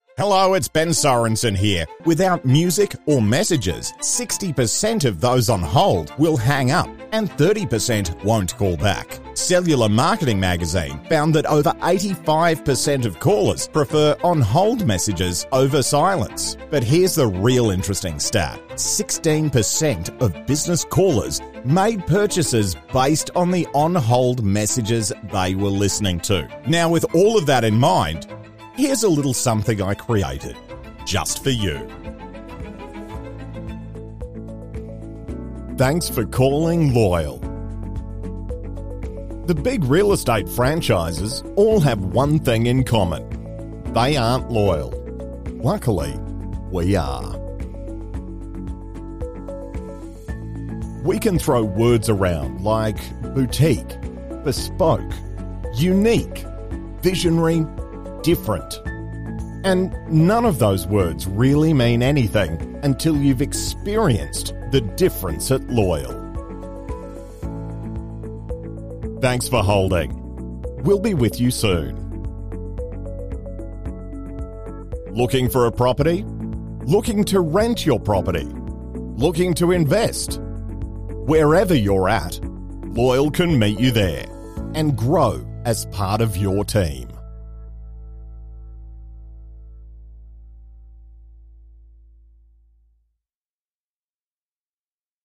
Male
IVR
BaritoneBassDeepLow